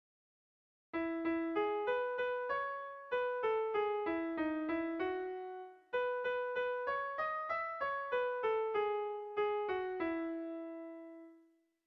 Bertso melodies - View details   To know more about this section
Irrizkoa
Lauko txikia (hg) / Bi puntuko txikia (ip)
AB